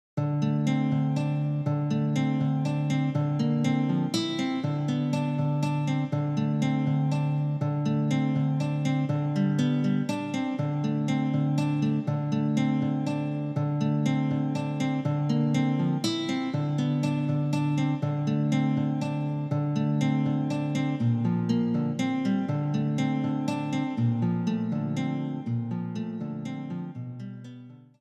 ギターも、ハープ同様サブ伴奏として使いますが、ピアノの伴奏との兼ね合いで、右奥に配置します。どちらかというと中低音を補う目的で、アルペジオを入れていきます。
luflenguitar.mp3